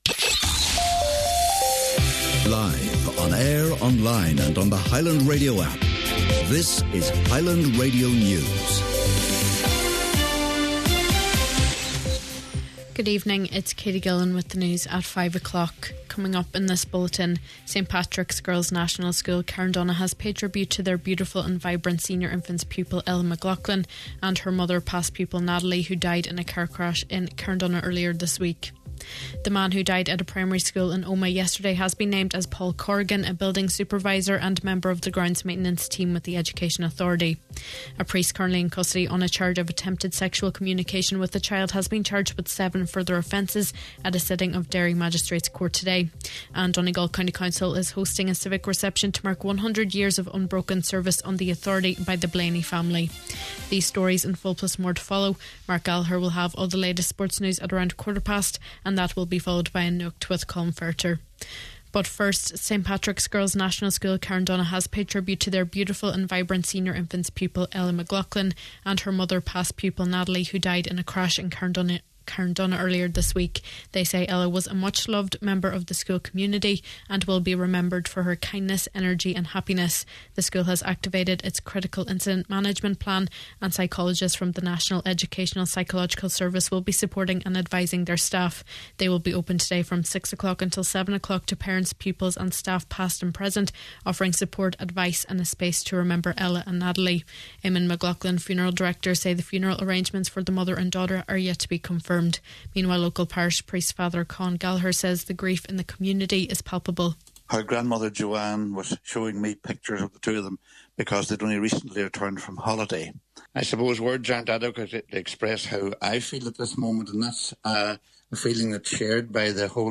Main Evening News, Sport, an Nuacht and Obituaries – Friday July 4th